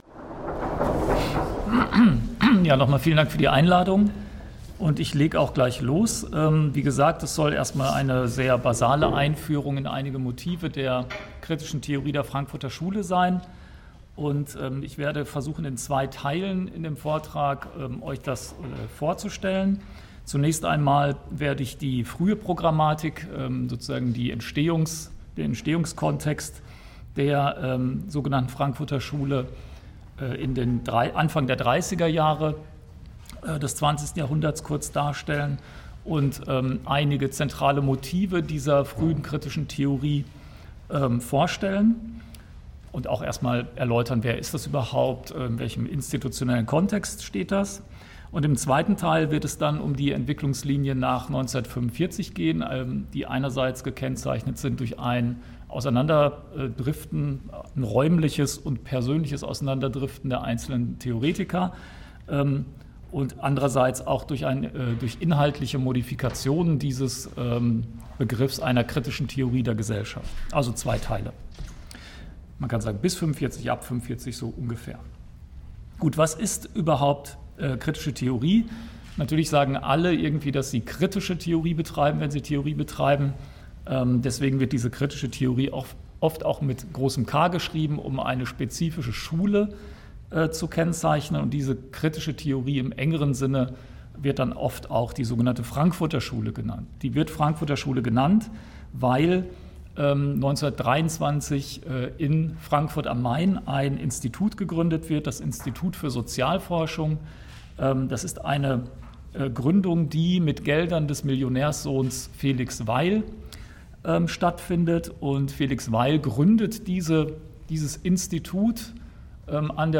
Audio-Mitschnitt, Universität Trier, 7.11.2018
Text/Vortrag im Archiv für kritische Gesellschaftstheorie